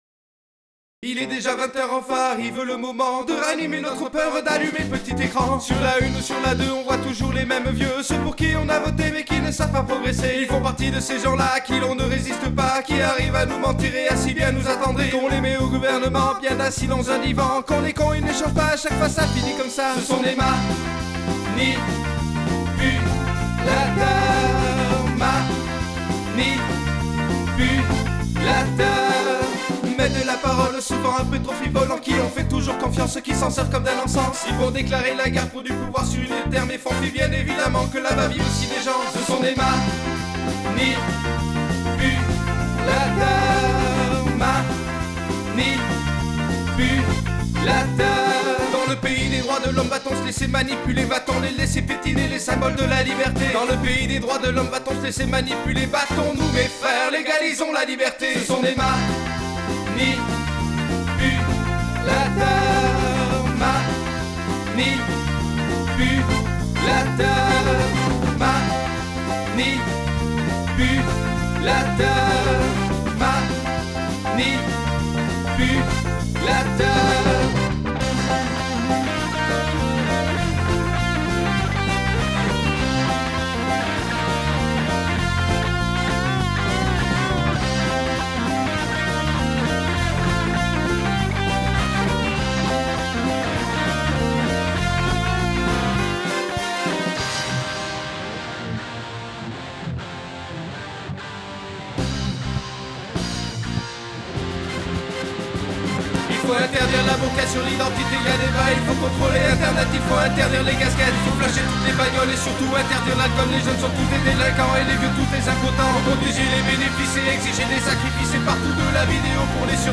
J'ai fait sauté tous les compresseurs sauf sur les voix.
J'ai en revanche retravaillé les égalisations de chaque instru, notamment la basse pour l'élargir.
J'ai baissé les 2ème voix mais je crois qu'une automatisation s'impose pour gérer les variations de volumes.
Je remarque aussi une mauvaise gestion des "A", qui piquent souvent.
J'ai écouter le clic, en effet, c'est le solo de gratte, donc...à refaire.